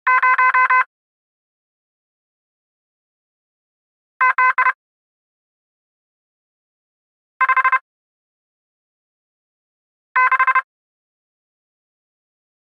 Morse Code Samples Var
yt_FfRfe5eVYbc_morse_code_samples_var.mp3